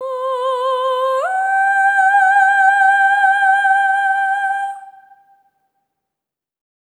SOP5TH C5 -L.wav